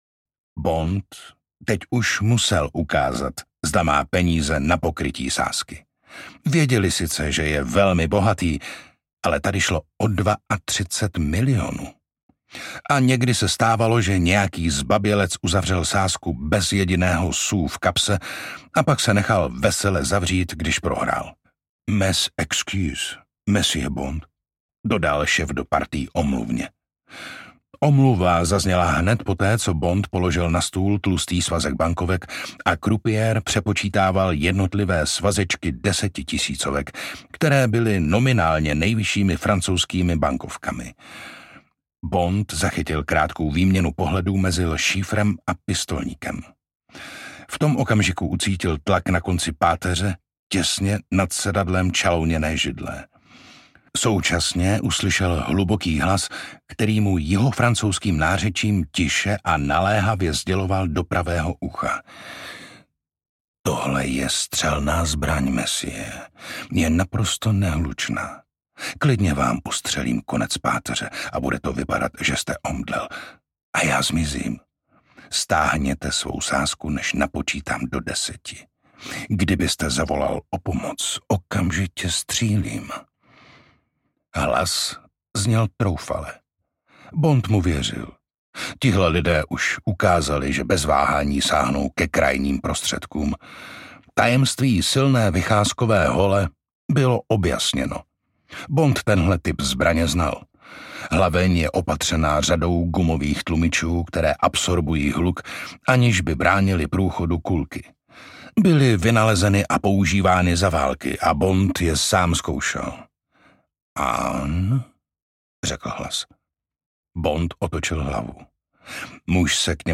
Casino Royale audiokniha
Ukázka z knihy
Čte Jiří Dvořák.
Vyrobilo studio Soundguru.